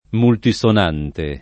vai all'elenco alfabetico delle voci ingrandisci il carattere 100% rimpicciolisci il carattere stampa invia tramite posta elettronica codividi su Facebook multisonante [ multi S on # nte ] (raro moltisonante ) agg.